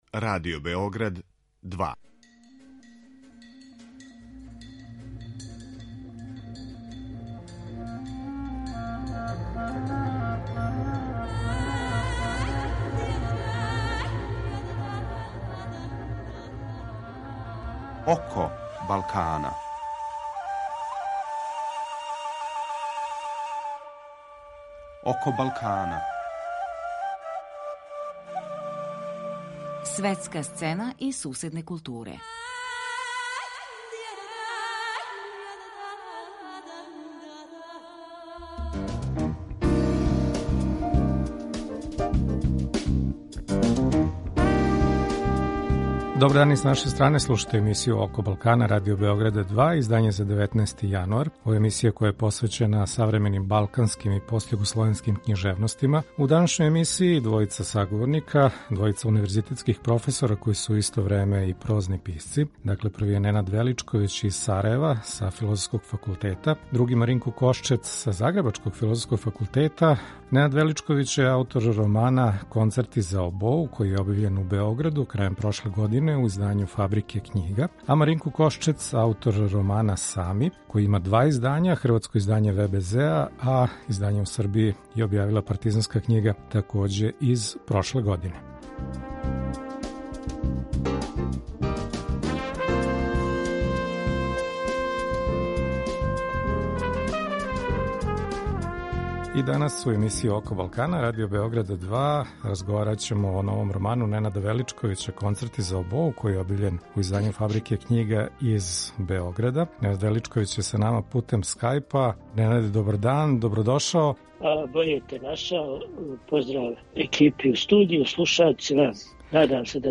У данашњој емисији наши саговорници су професори универзитета који су у исто време и прозни писци.